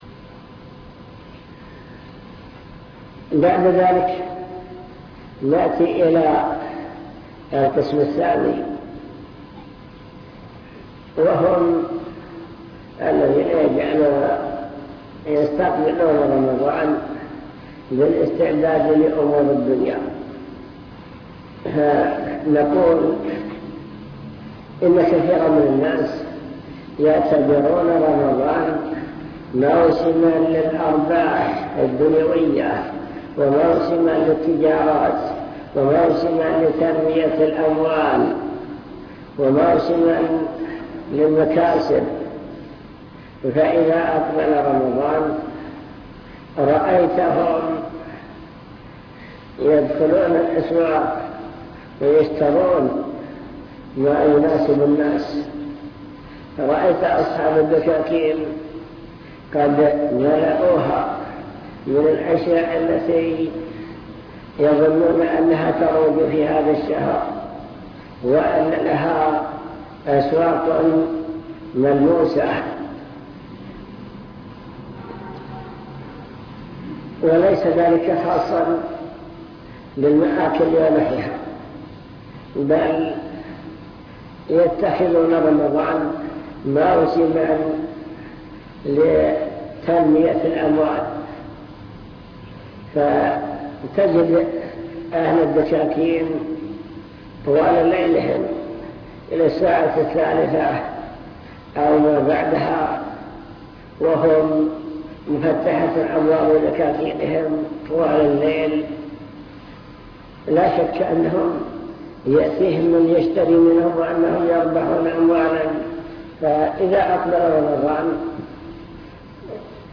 المكتبة الصوتية  تسجيلات - محاضرات ودروس  مجموعة محاضرات ودروس عن رمضان كيفية استقبال شهر رمضان